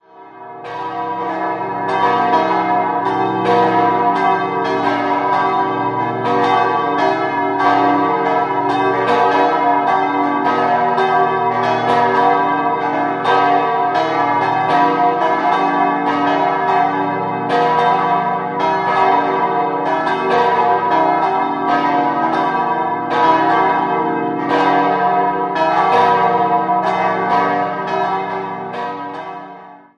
Idealquartett: h°-d'-e'-g'
Die für den Turm überdimensionierten Glocken läuten alle an teilweise stark gekröpften Stahljochen, zum Teil mit Obergewichten.